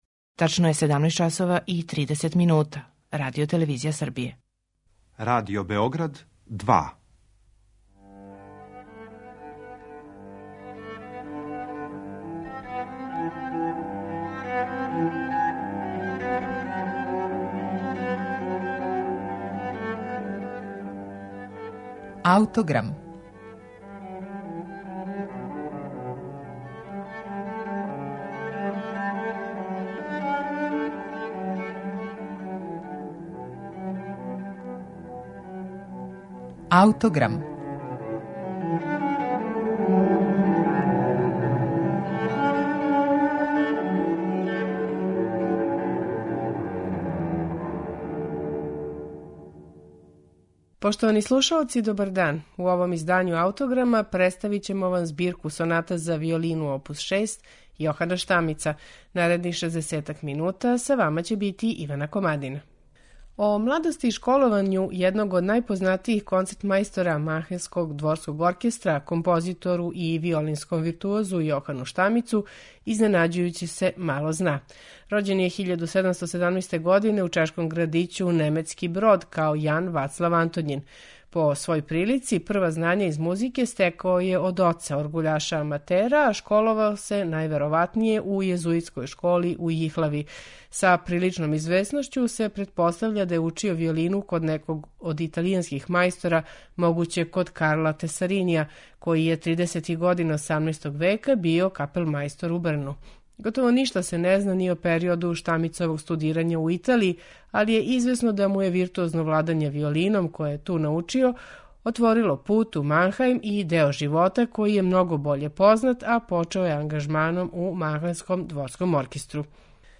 виолинисте
чембалисте